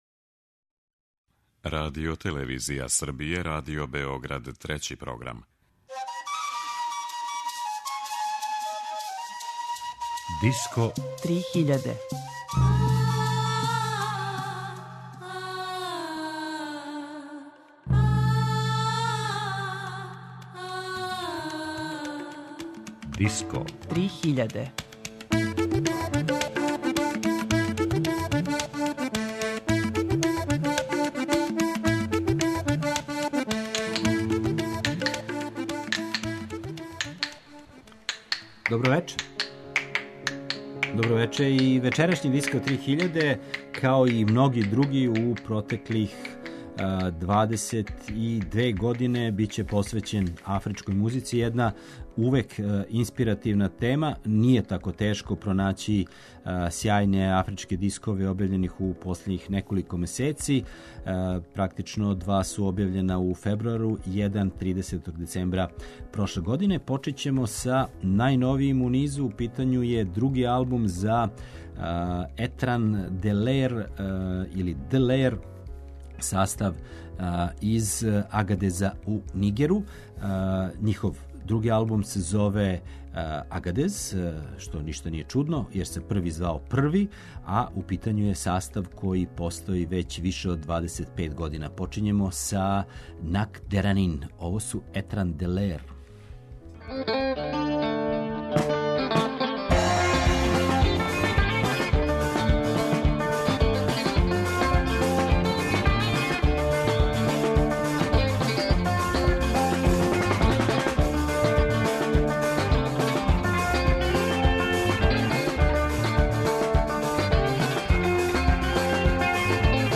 Музика Африке